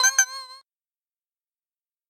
Звуки авторизации